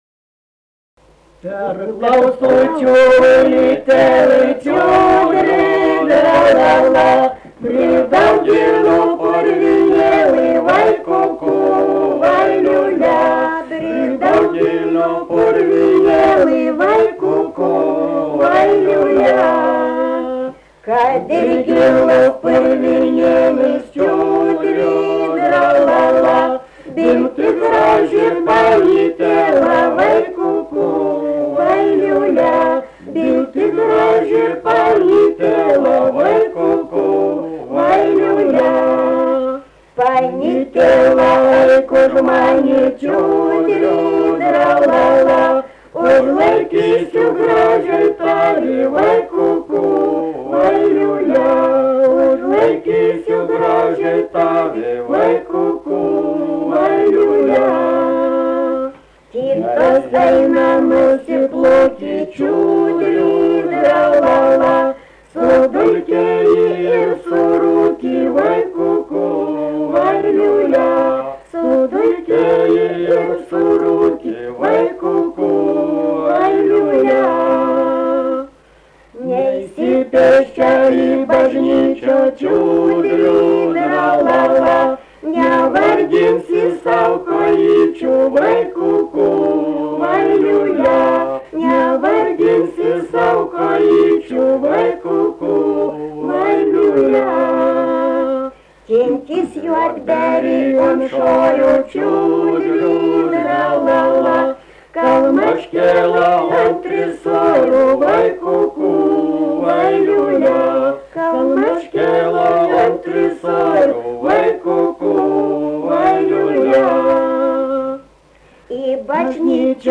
Biržai
vokalinis